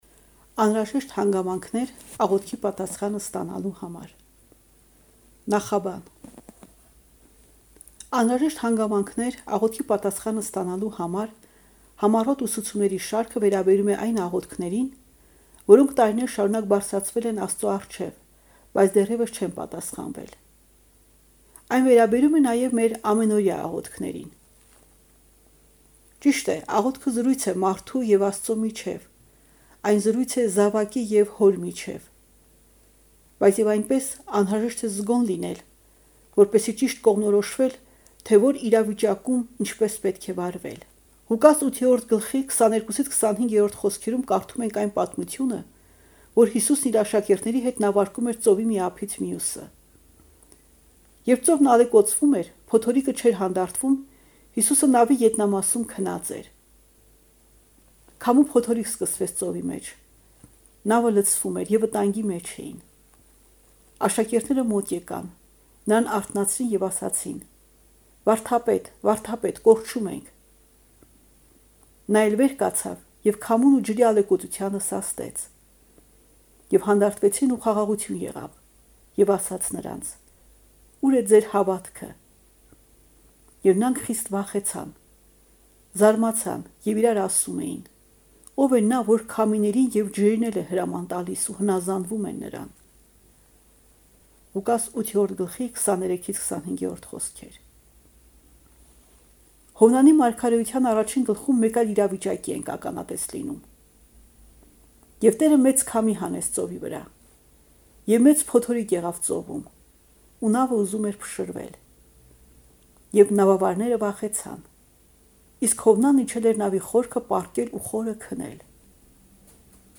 ԴԱՍ 1. ԵՂԻՈՒՍԻ ԽՈՍՔԸ ՀՈԲԻՆ։ ԱՆՀՐԱԺԵՇՏ ՀԱՆԳԱՄԱՆՔՆԵՐ ԱՂՈԹՔԻ ՊԱՏԱՍԽԱՆԸ ՍՏԱՆԱԼՈՒ ՀԱՄԱՐ